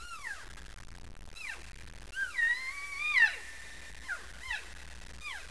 elkcow.wav